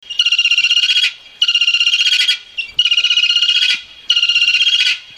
Vulturine guineafowl are hard to miss on the Kenyan savanna, be it due to their striking blue feathers, their piercing calls (click here for